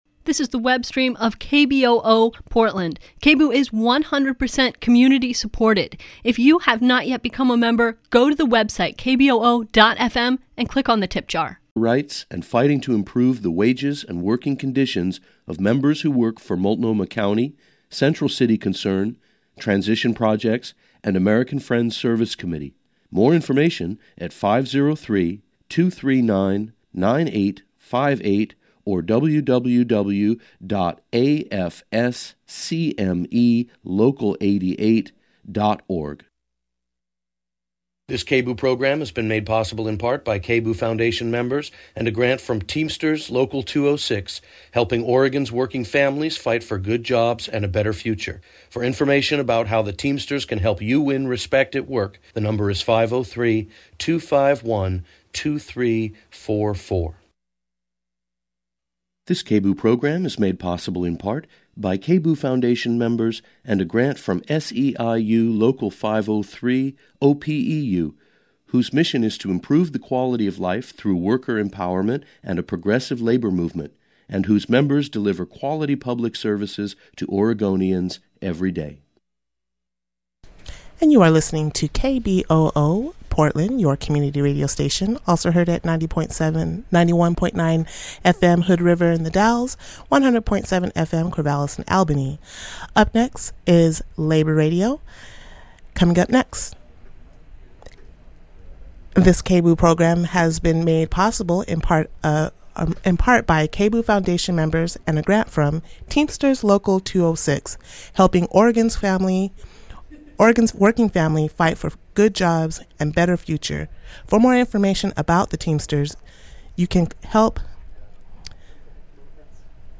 Download audio file Speaking worker truth to corporate power at the auto shows: Colombian and Mississippi autoworkers make their voices heard in Detroit. Hear their message from the rallies and press conferences at the big Cobo Center show, plus a live update from rank and file UAW member and a...